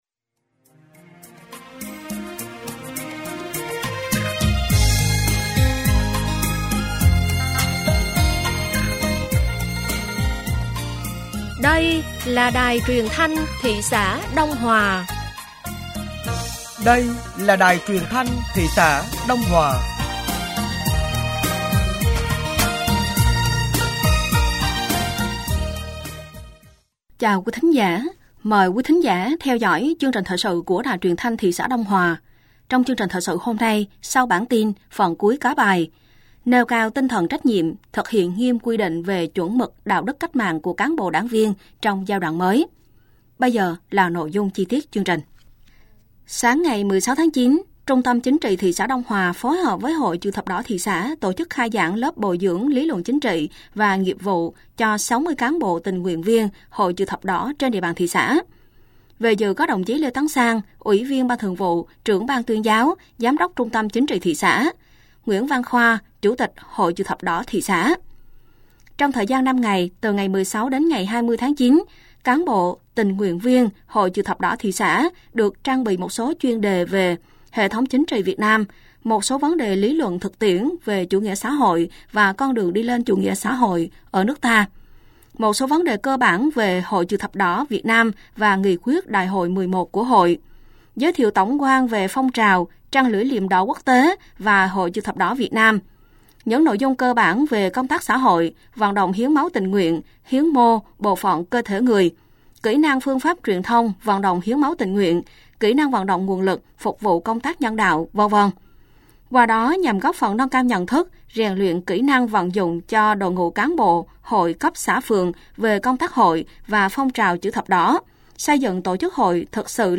Thời sự tối ngày 16 và sáng ngày 17 tháng 9 năm 2024